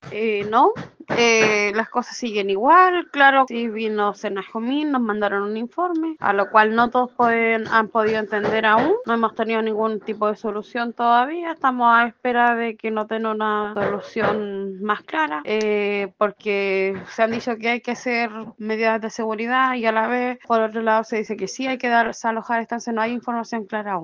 Una de las vecinas relató a Radio Bío Bío que están a la espera de una solución y acusó que la información es confusa porque, por un lado, les dicen que deben adoptar medidas de seguridad y, por otro, que tienen que desalojar.